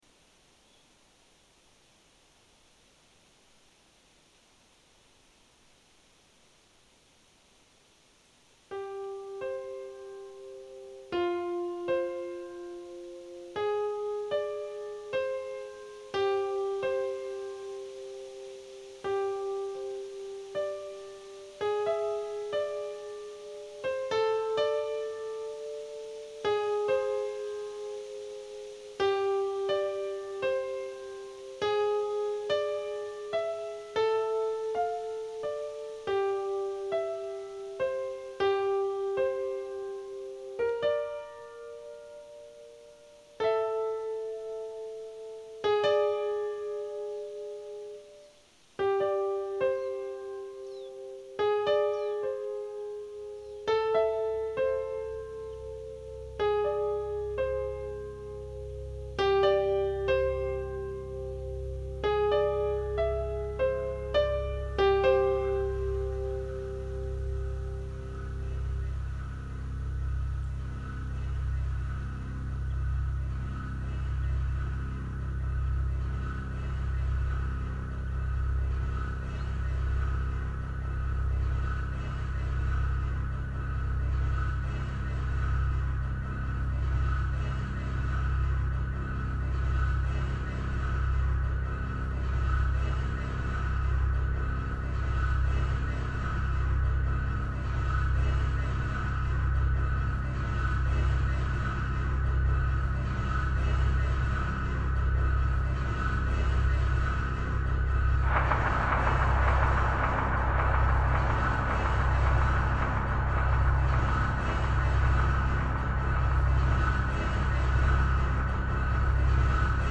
Music
noise drone melody segments
The slow, steady advance of the notes in this one (a few, pause, a few more than before) is like the careful, unwavering advance of a spider's legs on its web...